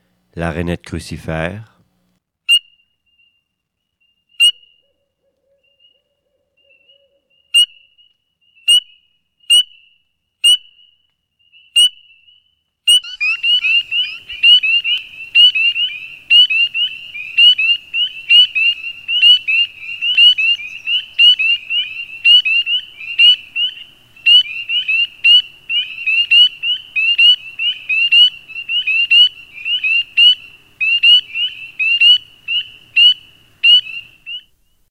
Rainette crucifère
rainette-crucifere.mp3